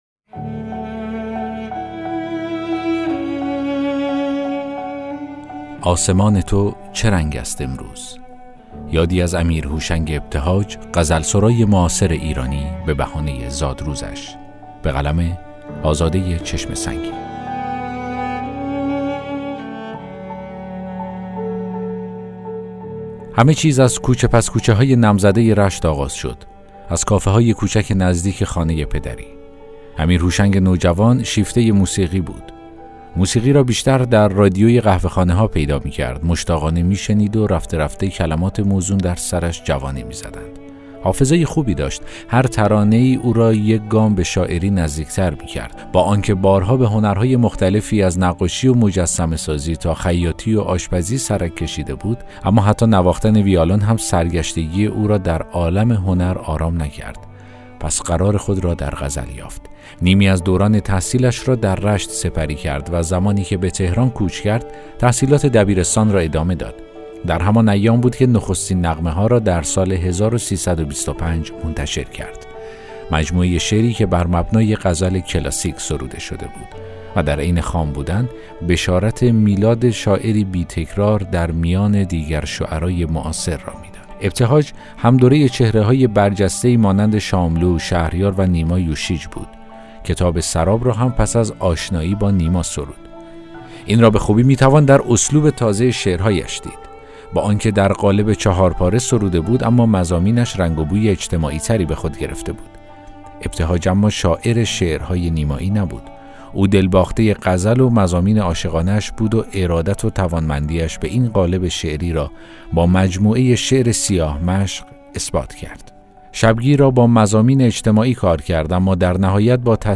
داستان صوتی: آسمان تو چه رنگ است امروز؟